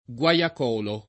[ gU a L ak 0 lo ]